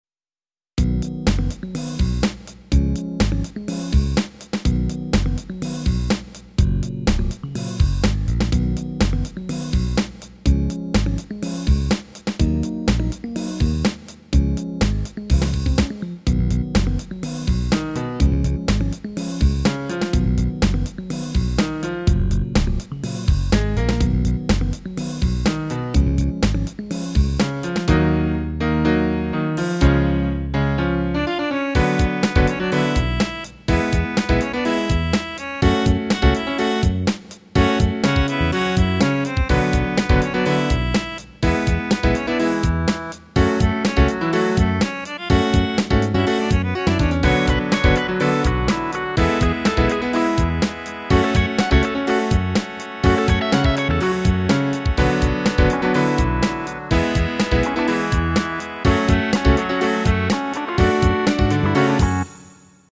It could be longer; the chord progression is good to solo over.
ROCK MUSIC ; LAMENTS